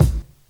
• Nineties Hip-Hop Kick G# Key 646.wav
Royality free kick drum sound tuned to the G# note. Loudest frequency: 690Hz
nineties-hip-hop-kick-g-sharp-key-646-LCh.wav